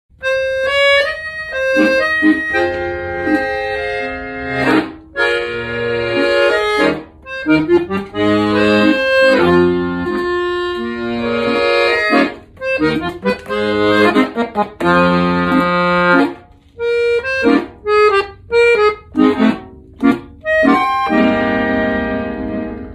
Just a little kitten to sound effects free download